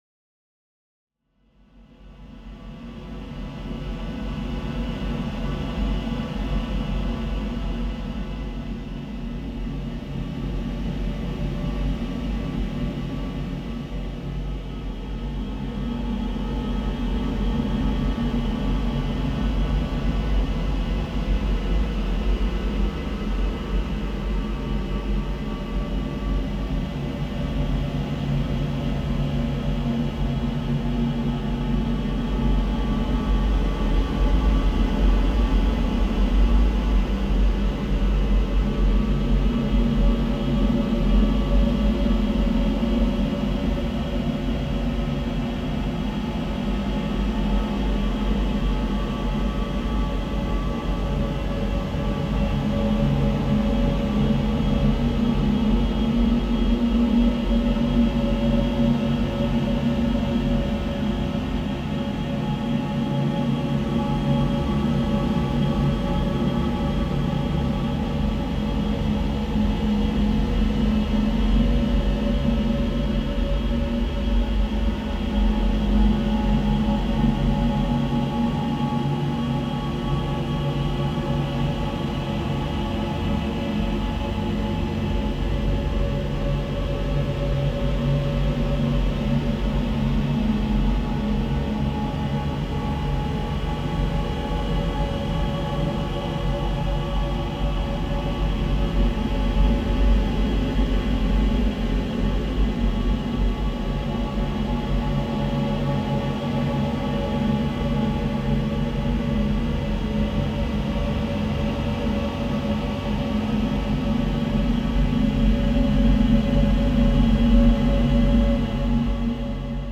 Threads of noise, tape loops, and samples.
Someone parked a truck across the road and left the engine running, for about 1 hour. One person’s infuriating background roar is another person’s granular fodder, I guess.